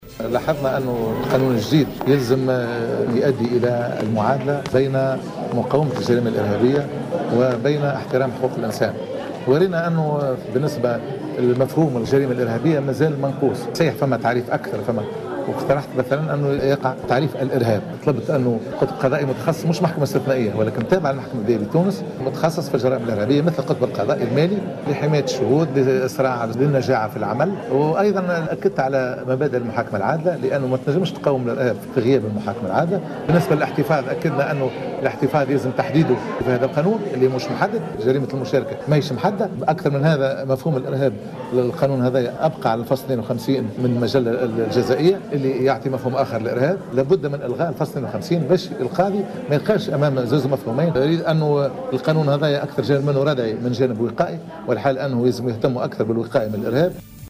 وقد دعا بن موسى في تصريح لجوهرة أف أم إلى إلغاء الفصل 52 من قانون الإرهاب الذي من المفترض أن يوفّق بين مقاومة الجريمة الإرهابية واحترام حقوق الإنسان.